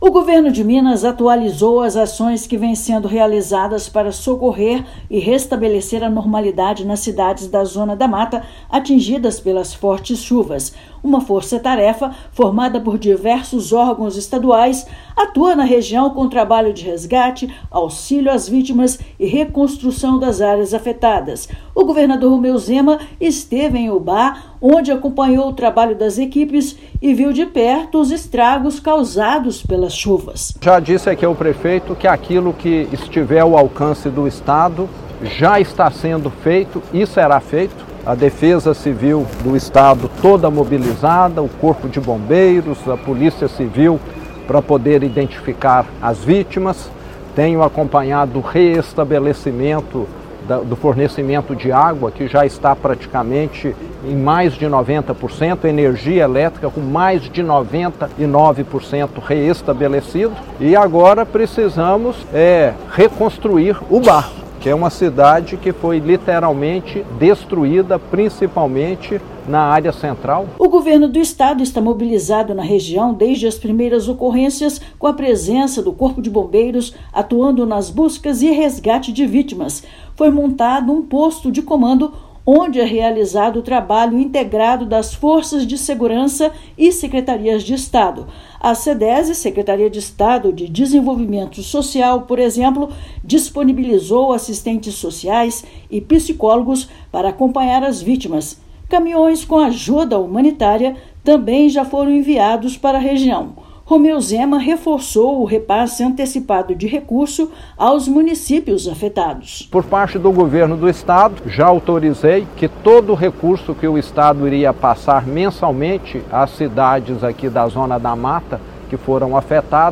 [RÁDIO] Governo de Minas reforça apoio às cidades da Zona da Mata atingidas pelas chuvas
Força-tarefa está na região para auxiliar no resgate e oferecer suporte às vítimas. Ouça matéria de rádio.